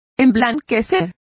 Complete with pronunciation of the translation of whitened.